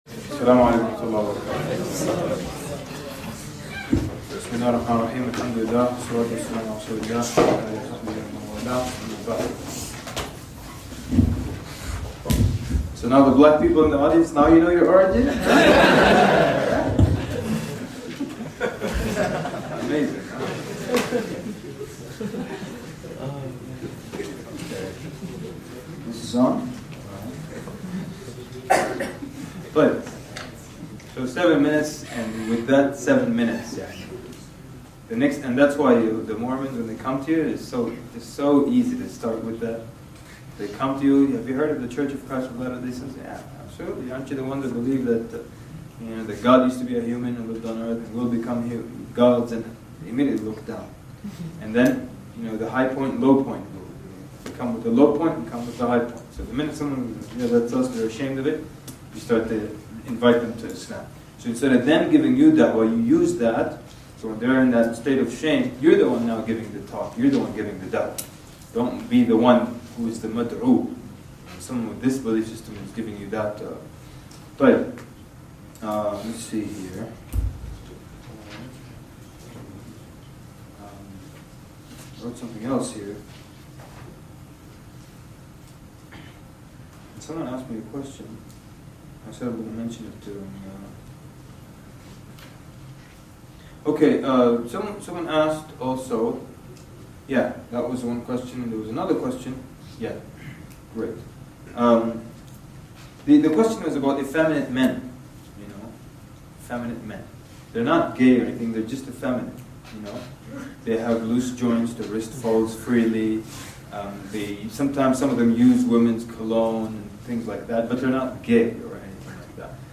It was given over the weekend in Calgary, Canada to prepare the students for ...